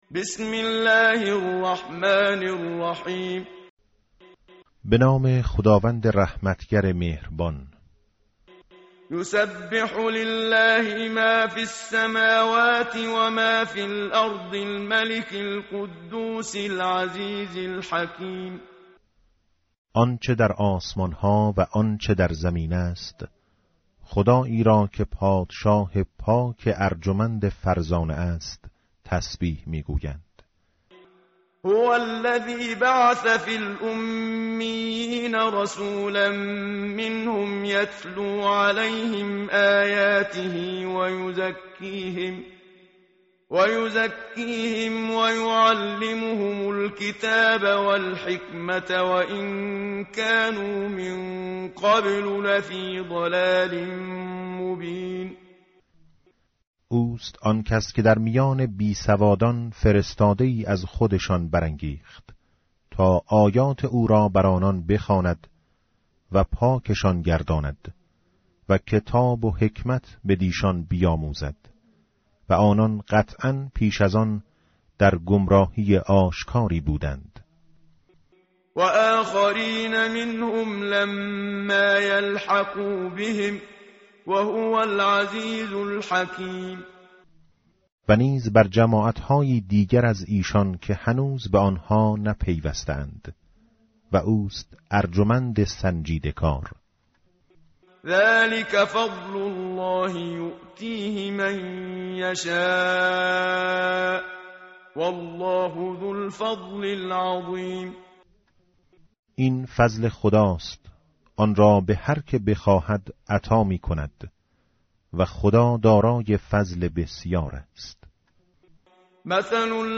tartil_menshavi va tarjome_Page_553.mp3